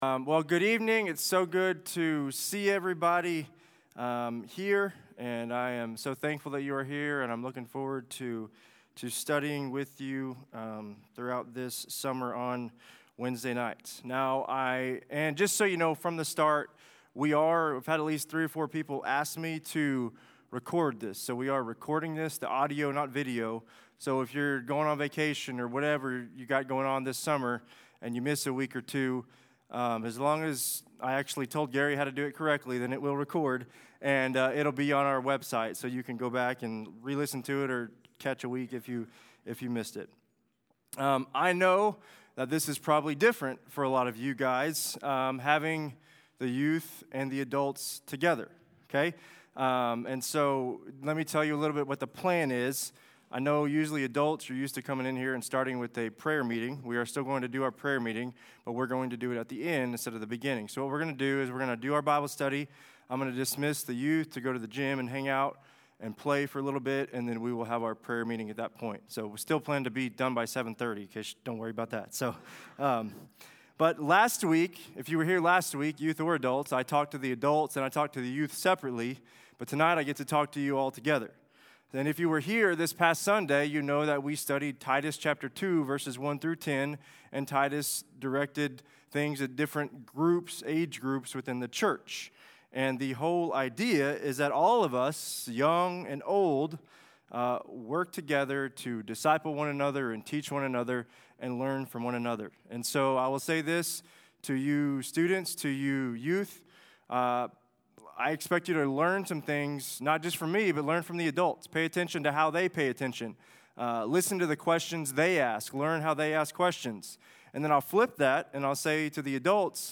Sermons | Kentuckytown Baptist Church